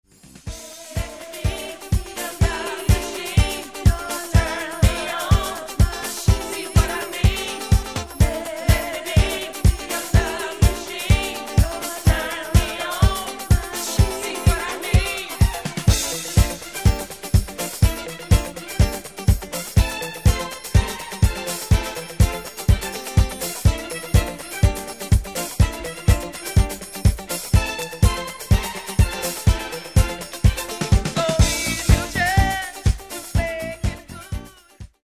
Genere:   Disco | Funky
[12''Mix Extended]